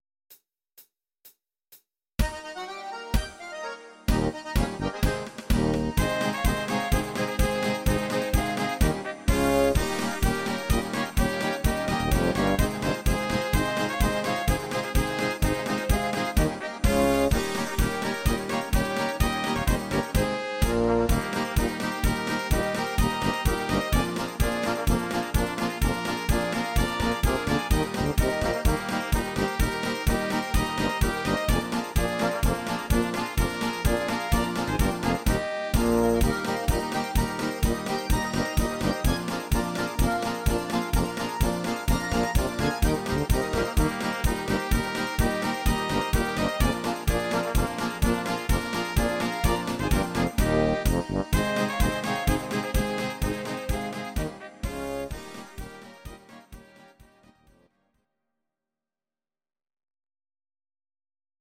These are MP3 versions of our MIDI file catalogue.
Your-Mix: Volkstï¿½mlich (1262)